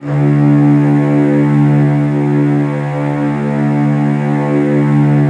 CELLOS EN2-L.wav